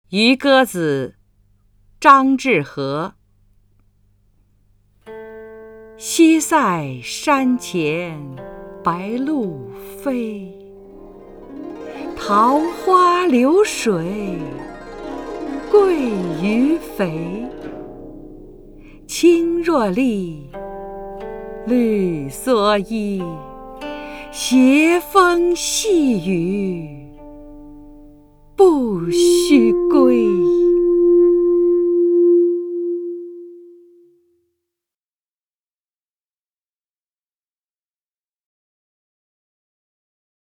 张筠英朗诵：《渔歌子·西塞山前白鹭飞》(（唐）张志和)
YuGeZiXiSaiShanQianBaiLuFei_ZhangZhiHe(ZhangJunYing).mp3